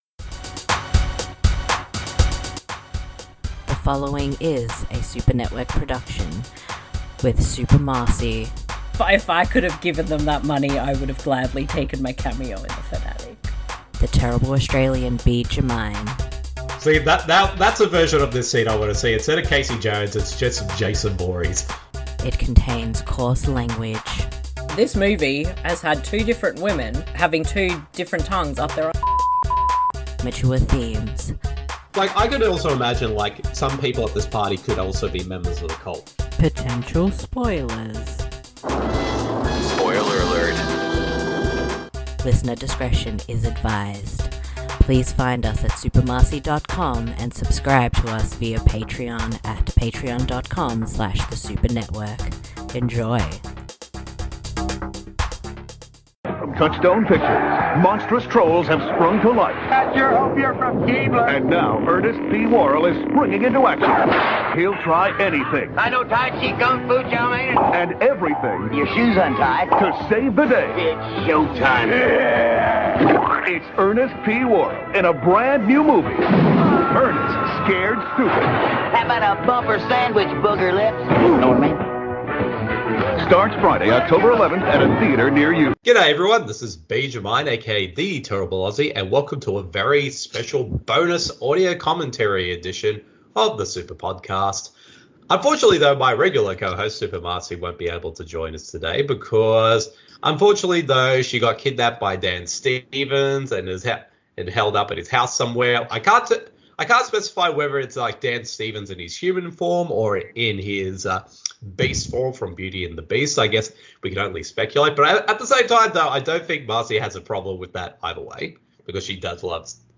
Audio Commentary